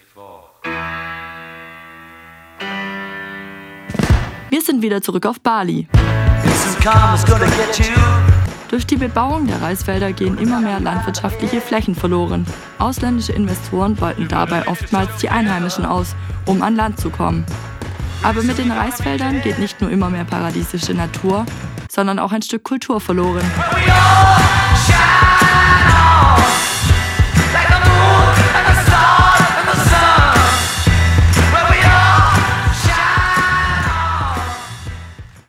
Paradies Bali: Zerstört der Massentourismus die Insel? Feature, Teil 4 (488)